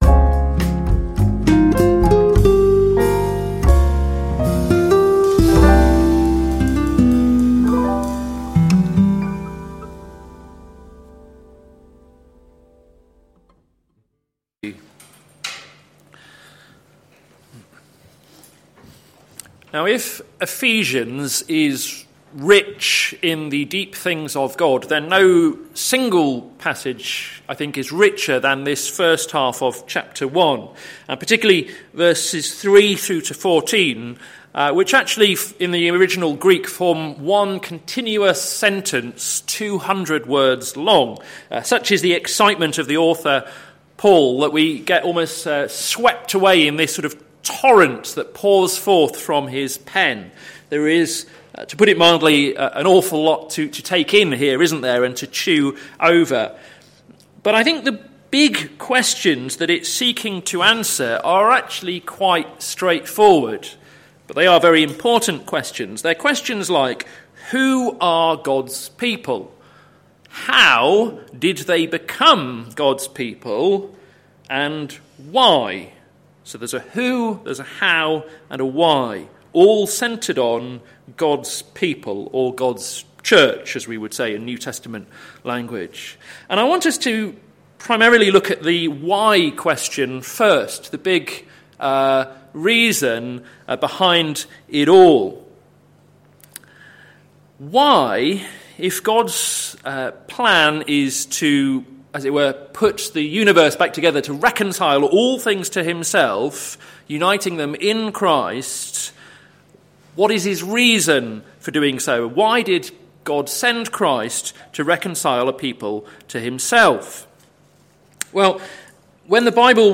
Sermon Series - Glory in the Church - plfc (Pound Lane Free Church, Isleham, Cambridgeshire)